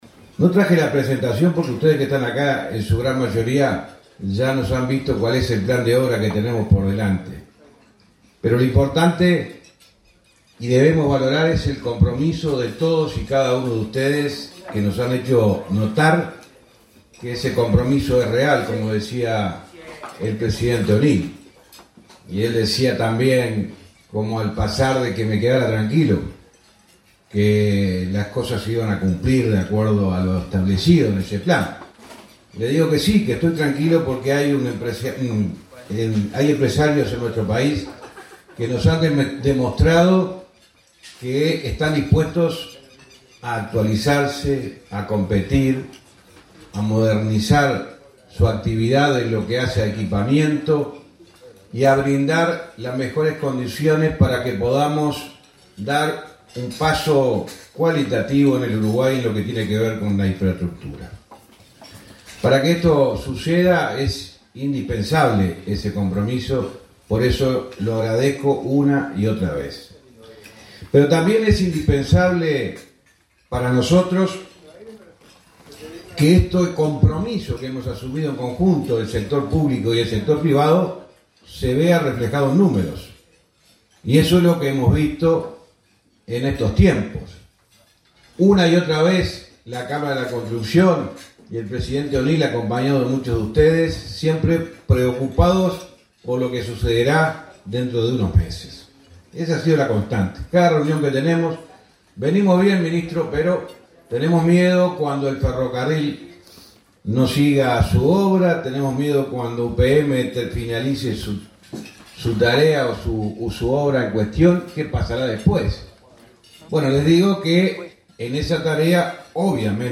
Palabras del ministro de Transporte, José Luis Falero
El ministro de Transporte, José Luis Falero, participó este lunes 17 en el Club de Golf, en Montevideo, en un acto por el Día de la Construcción.